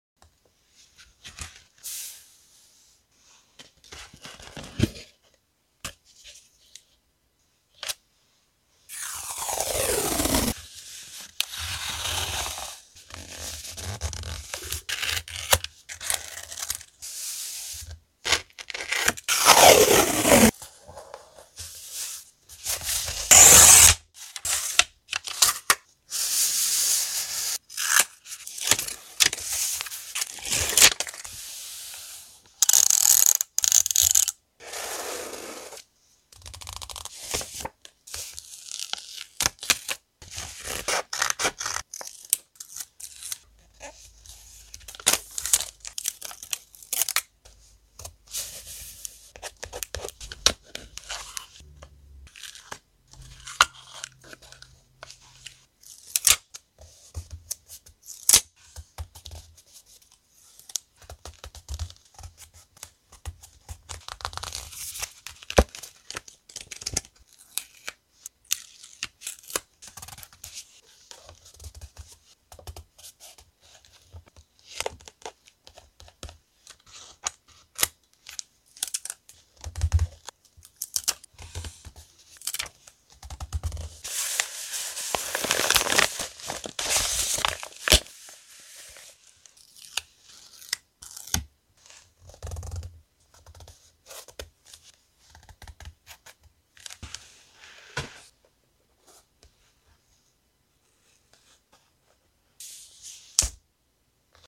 ASMR | Mood.